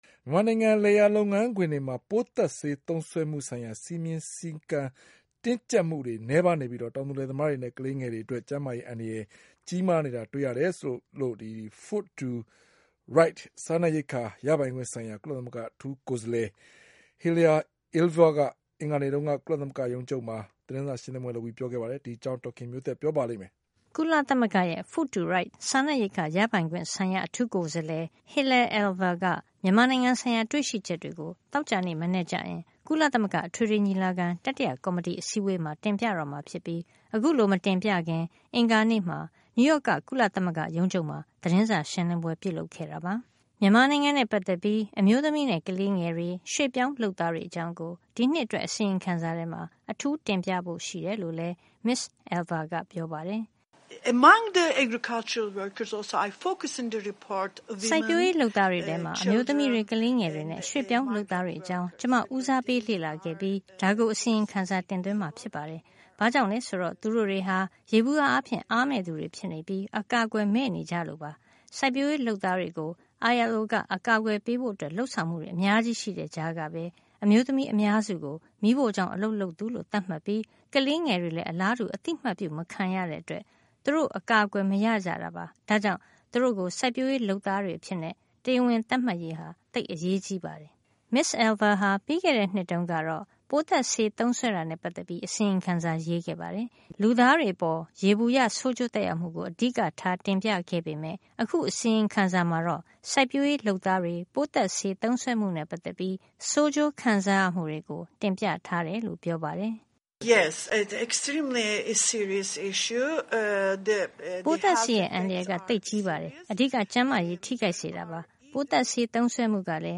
မြန်မာနိုင်ငံရဲ့ လယ်ယာလုပ်ငန်းခွင်တွေမှာ ပိုးသတ်ဆေး သုံးစွဲမှုဆိုင်ရာ စည်းမျဉ်းစည်းကမ်း ကြပ်မတ်မှုနည်းပါးပြီး တောင်သူလယ်သမားတွေနဲ့ ကလေးငယ်တွေအတွက် ကျန်းမာရေး အန္တရာယ် ကြီးကြီးမားမား ရှိနေတာ တွေ့ရကြောင်း Food to Right စားနပ်ရိက္ခာ ရပိုင်ခွင့်ဆိုင်ရာ ကုလသမဂ္ဂ အထူးကိုယ်စားလှယ် Hilal Elver က အင်္ဂါနေ့ ကုလသမဂ္ဂရုံးချုပ်မှာ ပြုလုပ်တဲ့ သတင်းစာရှင်းလင်းပွဲမှာ ပြောကြားခဲ့ပါတယ်။